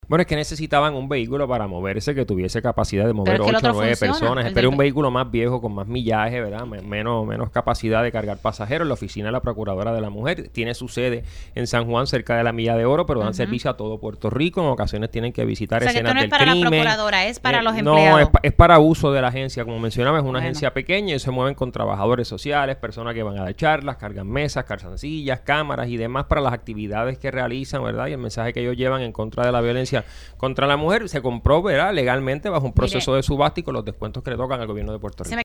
422-HIRAM-TORRES-MONTALVO-SEC-ASUNTOS-PUBLICOS-DEFIENDE-COMPRA-DE-GUAGUA-DE-LUJO-EN-PROCURADORIA-DE-LAS-MUJERES.mp3